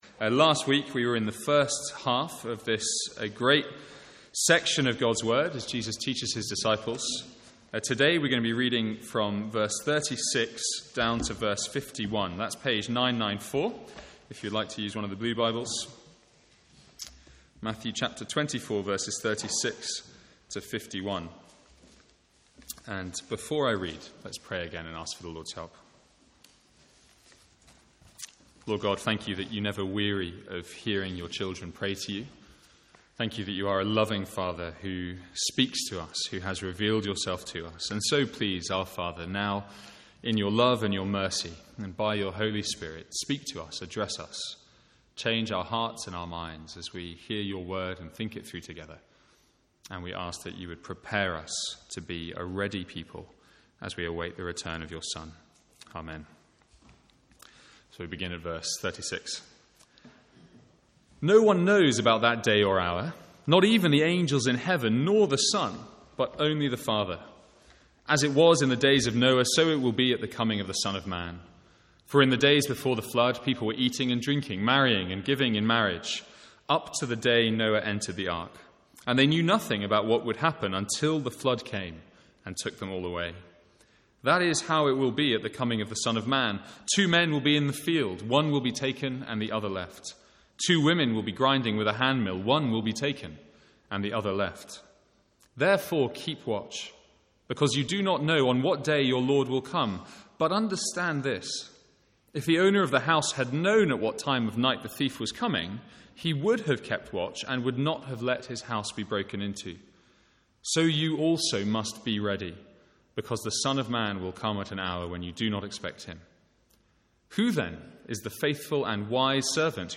Sermons | St Andrews Free Church
From the Sunday morning series in Matthew's gospel.